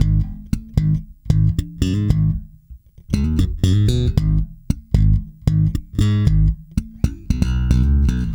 -JP THUMB G#.wav